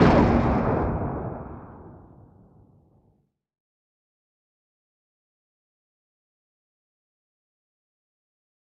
LaunchRobot.wav